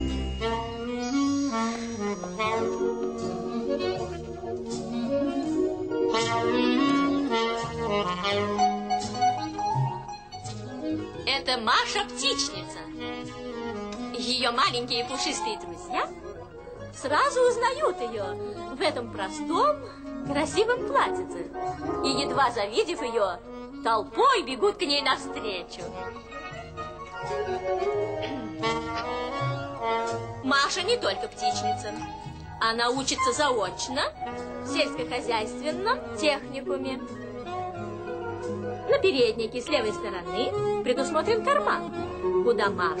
в данном конкретном случае - похоже инструментал ...